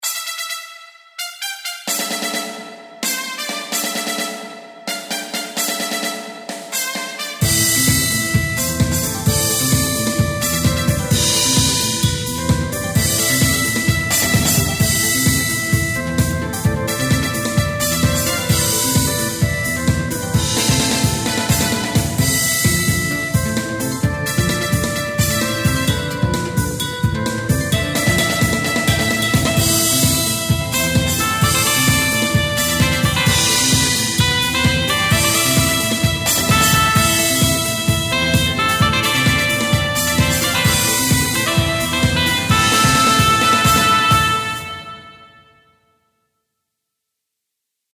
アップテンポ激しい
BGM